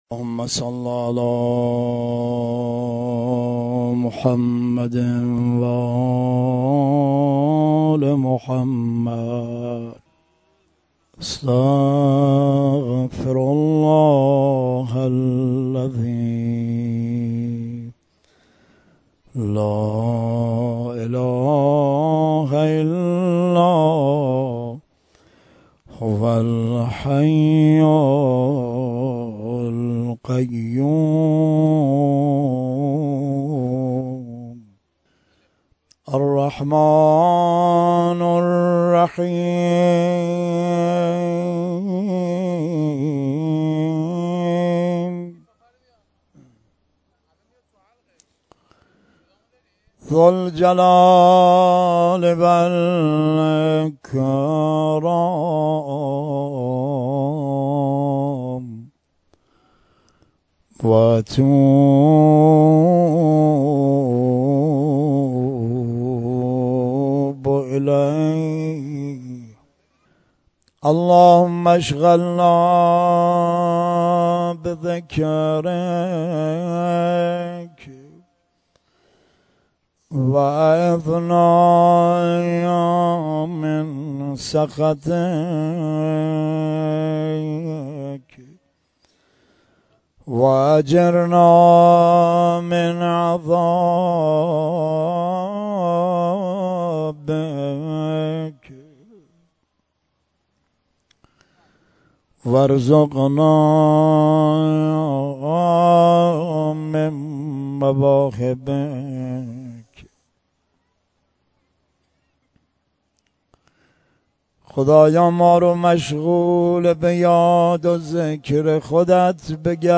مناجات خوانی
در مصلی تهران شب نهم ماه رمضان 93